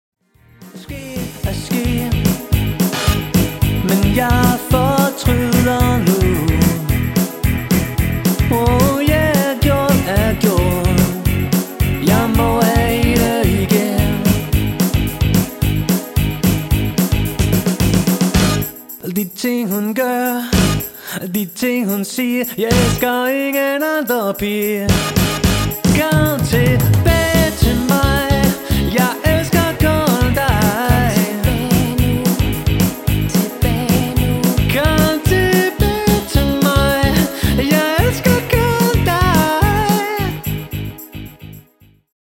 Dansemusik for alle aldre.
• Coverband